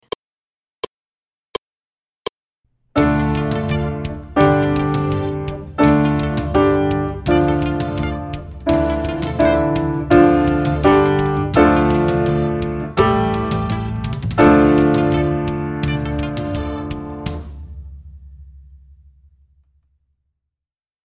합주